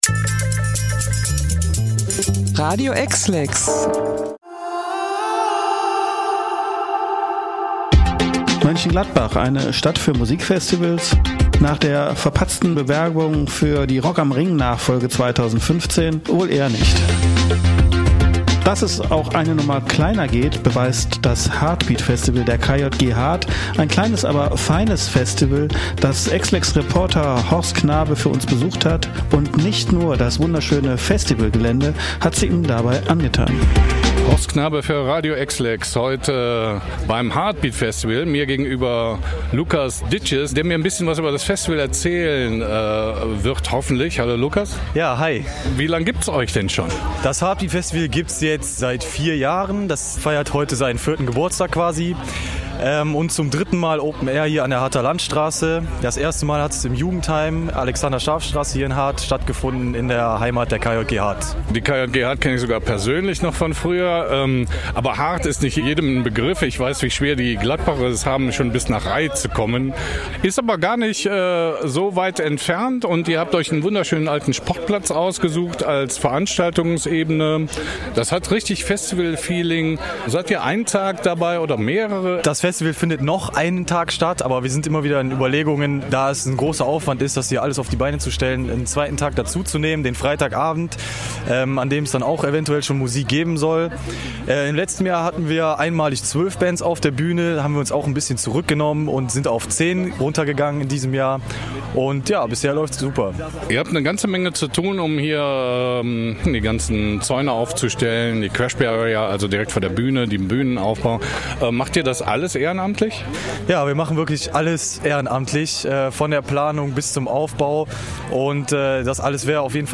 Viel Spaß außerdem mit der Hamburger Band „Brett“ und ihrem Song „Ein schöner Tag“.